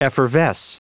Prononciation du mot effervesce en anglais (fichier audio)
Prononciation du mot : effervesce